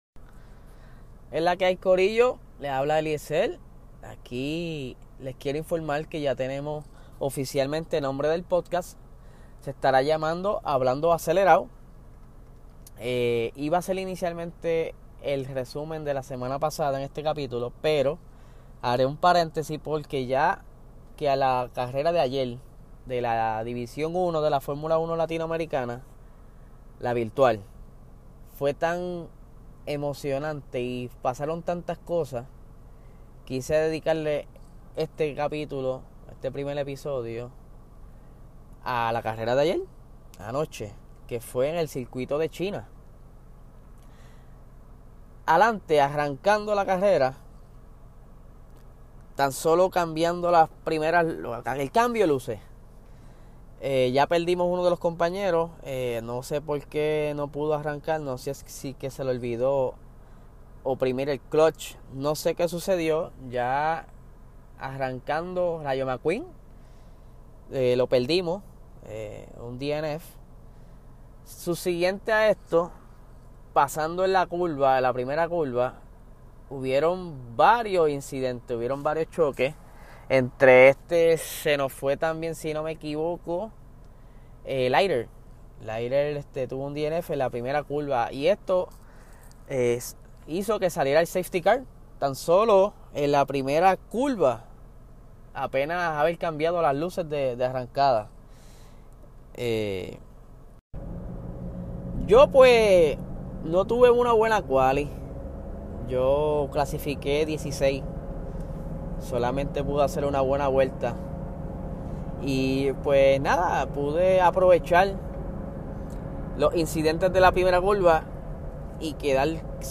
En este pequeño episodio hice un resumen de lo ocurrido en la carrera virtual de la liga latinoamericana de F1 2020. Ojo, grabe por momentos, no edite asi que perdonen los errores.